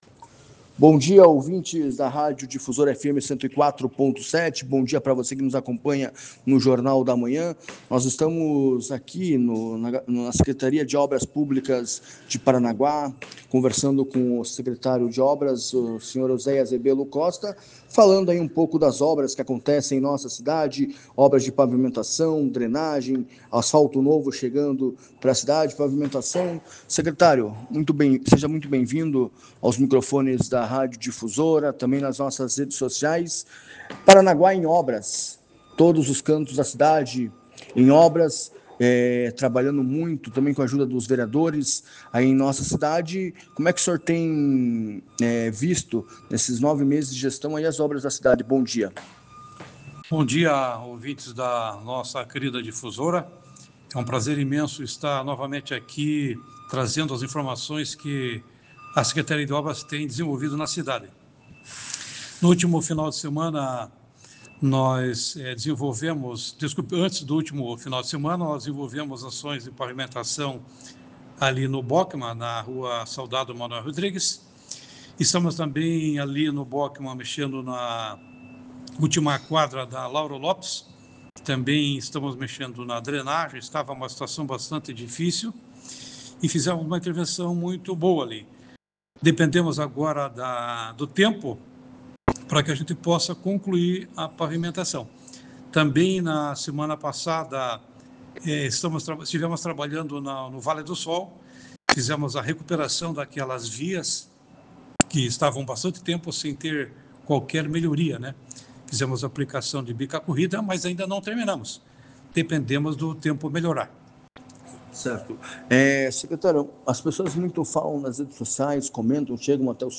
Ozéias Rebello Costa detalhou, em entrevista à Rádio Difusora, os trabalhos realizados e as próximas etapas do cronograma de obras
O secretário municipal de Obras Públicas de Paranaguá, Ozéias Rebello Costa, concedeu entrevista à Rádio Difusora FM 104.7 e apresentou um panorama das ações executadas pela Prefeitura nos últimos meses. As frentes de trabalho incluem pavimentação, drenagem e recuperação de vias, beneficiando diferentes bairros da cidade.